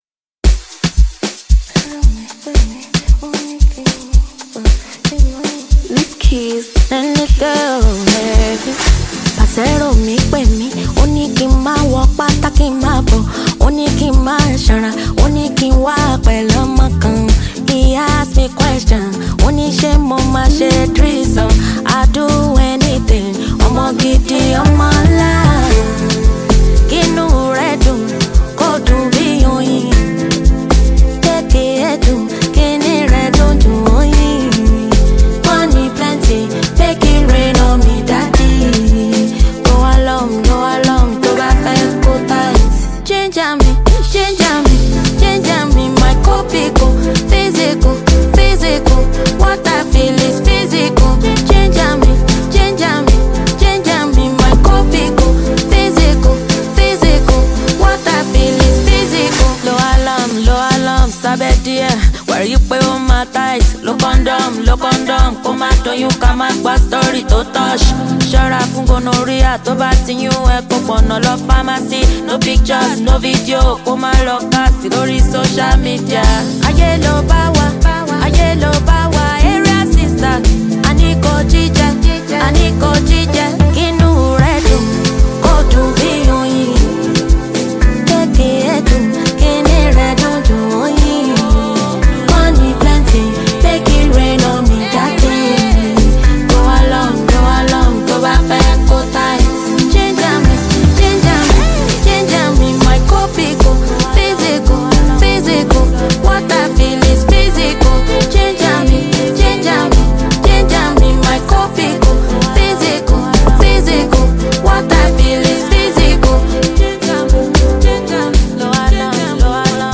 With pulsating beats and captivating melodies
Afrobeats